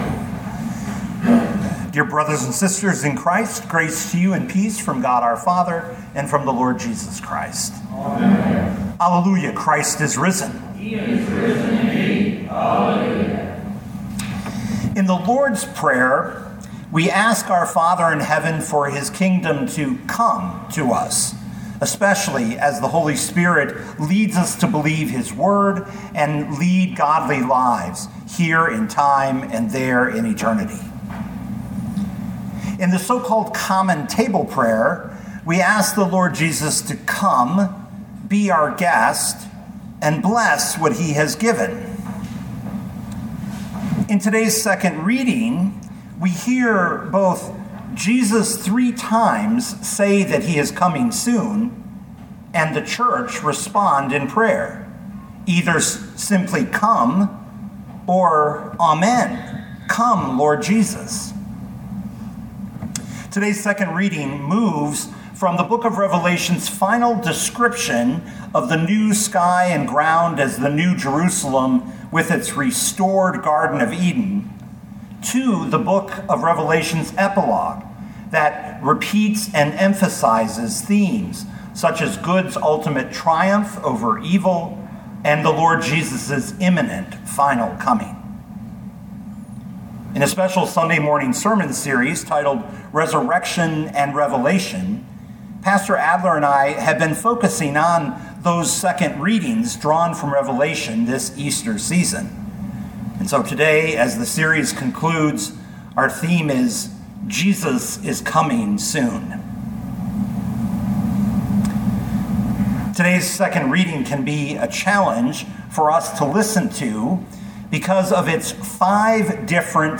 2025 Revelation 22:1-20 Listen to the sermon with the player below, or, download the audio.